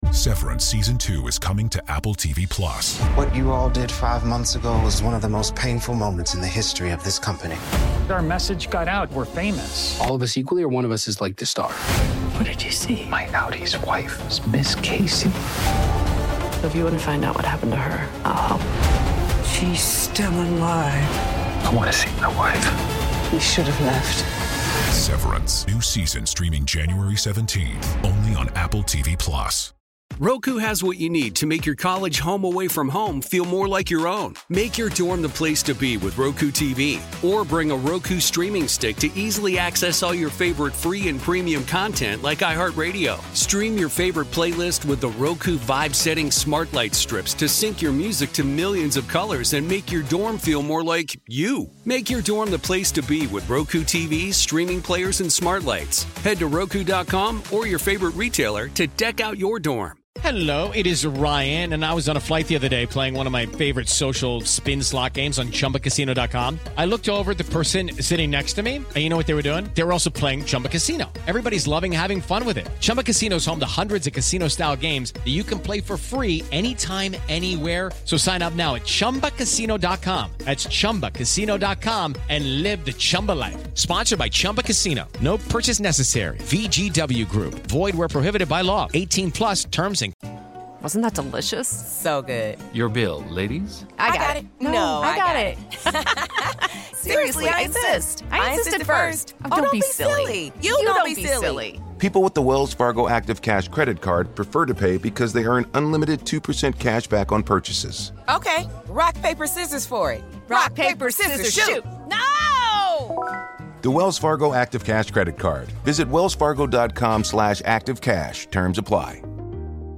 At the National Archive, McCullough names and thanks his own teachers, and hands down the important right way to teach and study our history.